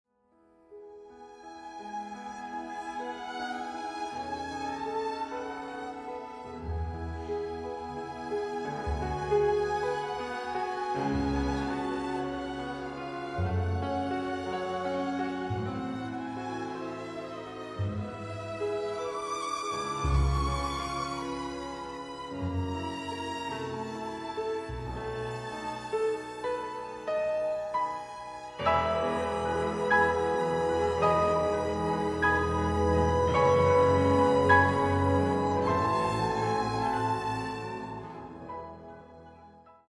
This evening’s celebration concert
piano concerto